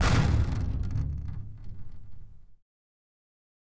Drama Boom 02
Boom Cinematic Comedy Drama Impact Punchline Trailer sound effect free sound royalty free Memes